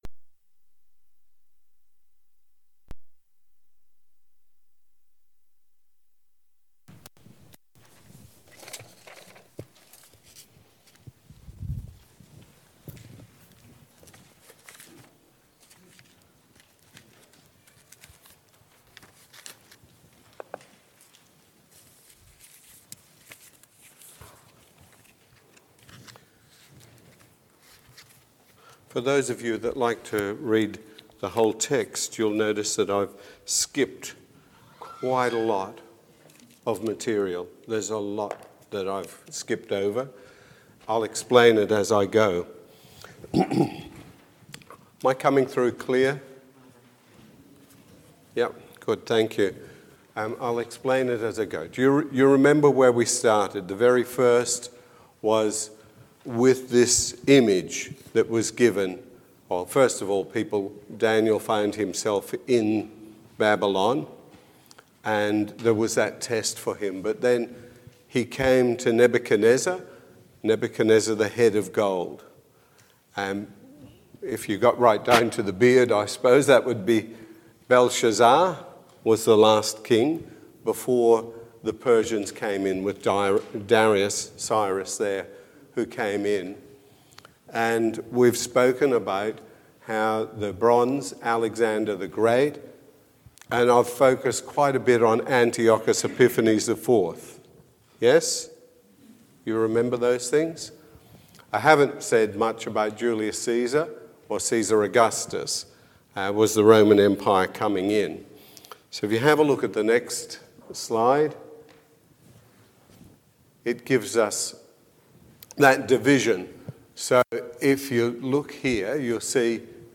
The Book of Daniel Passage: Daniel 10, 11, and 12 Service Type: Sunday morning service « The Eternal Kingdom Christ the King